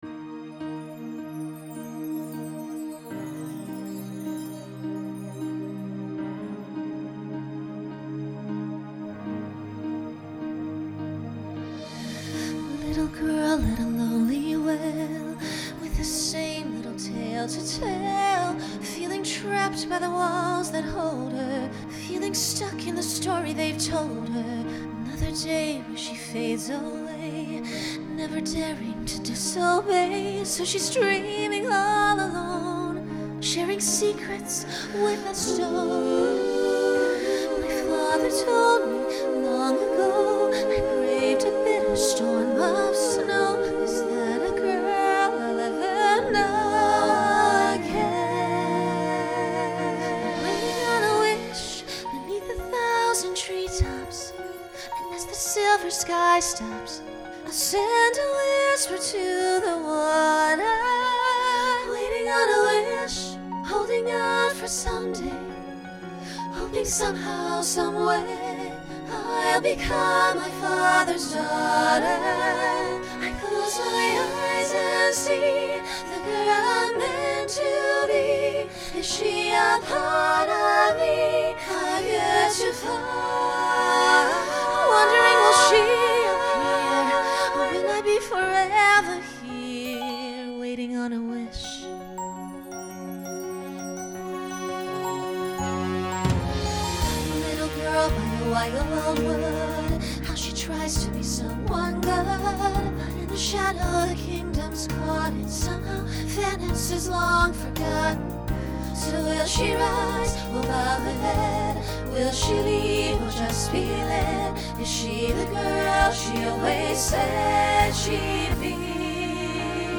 Genre Broadway/Film Instrumental combo
Ballad , Solo Feature Voicing SSA